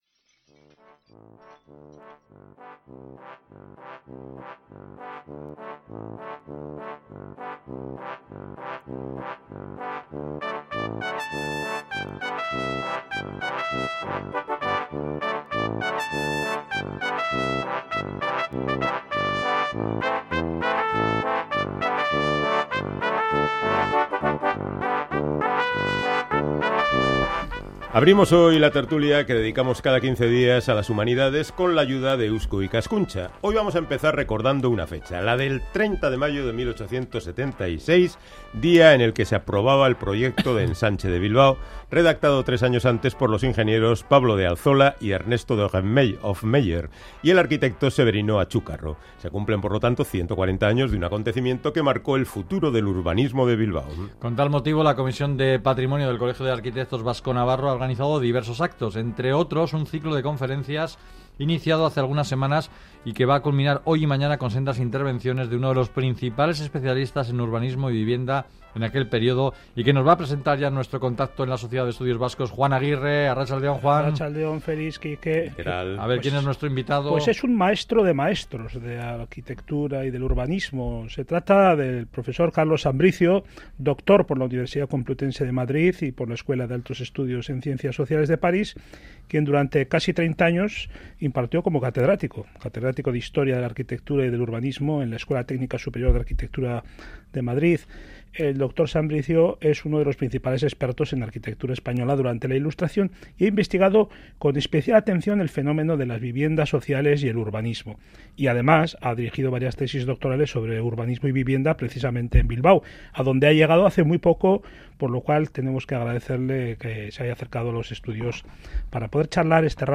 conversamos sobre los ensanches urbanos vascos